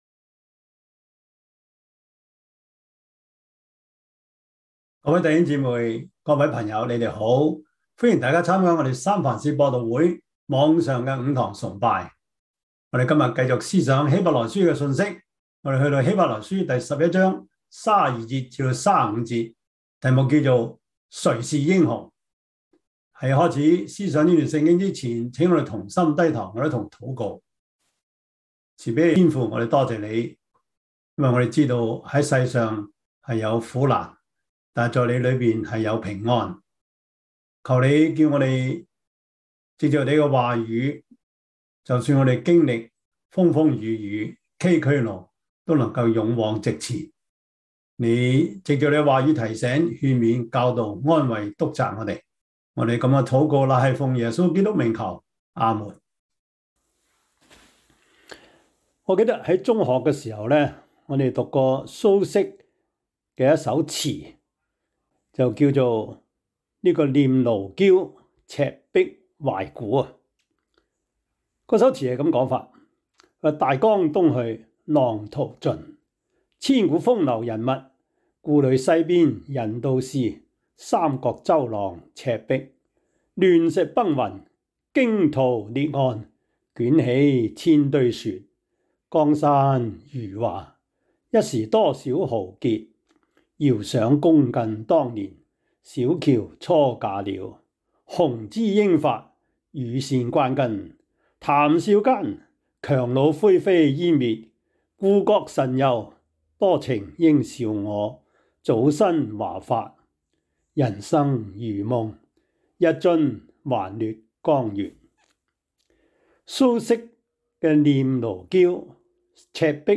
希伯來書 11:32-35 Service Type: 主日崇拜 希 伯 來 書 11:32-35 Chinese Union Version
Topics: 主日證道 « 忠言逆耳 摩西五經 – 第十二課 »